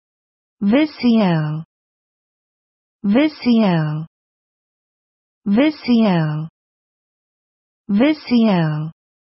visio软件的读音在线收听
英音[vɪʃɪəʊ] 美音[vɪʃɪoʊ] 其中第一个音节的元音是短音/i/，类似于中文的“一”；第二个音节的元音是长音/oʊ/，类似于中文的“哦”。